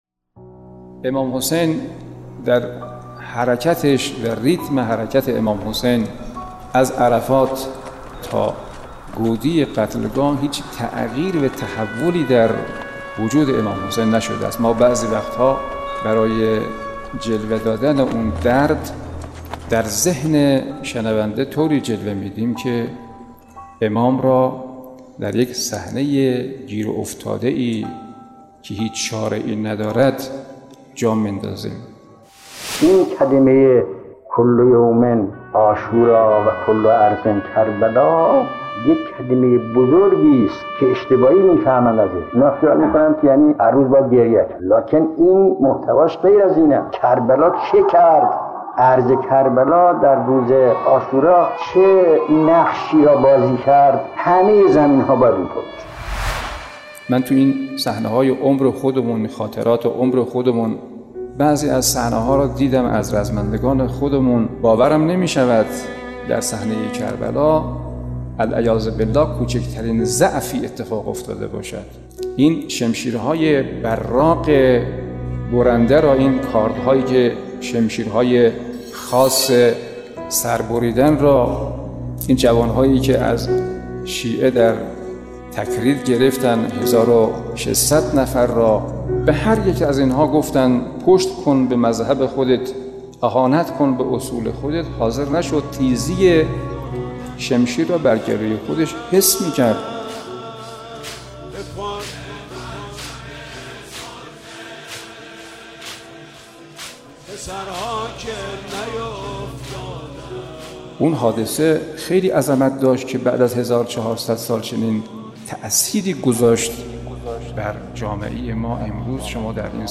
در بخشی از سخنان سردار سلیمانی درباره سبک و سیره امام حسین(ع) آمده است: در حرکت امام حسین(ع) از عرفات تا گودی قتلگاه هیچ تغییر و تحولی در وجود امام حسین(ع) بوجود نیامد، اما بعضی وقت‌ها برای جلوه دادن آن درد، امام را در یک صحنه گیر افتاده‌ای جا می‌اندازیم.